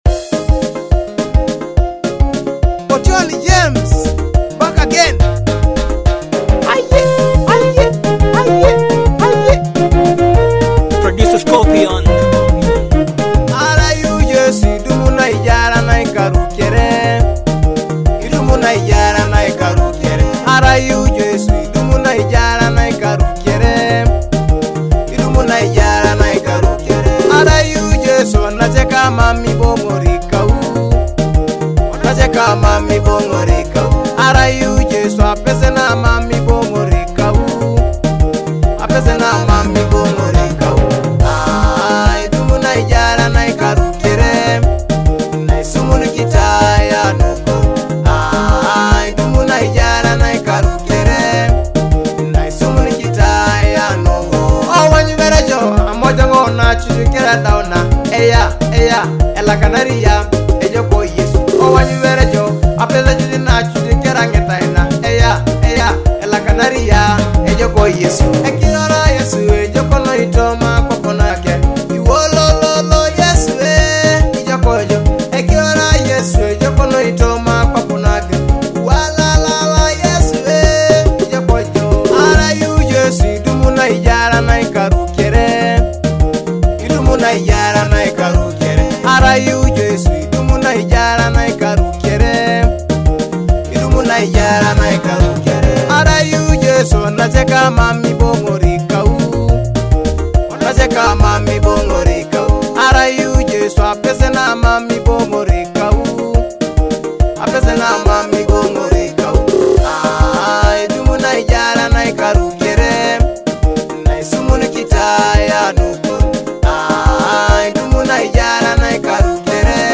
heartfelt Teso gospel song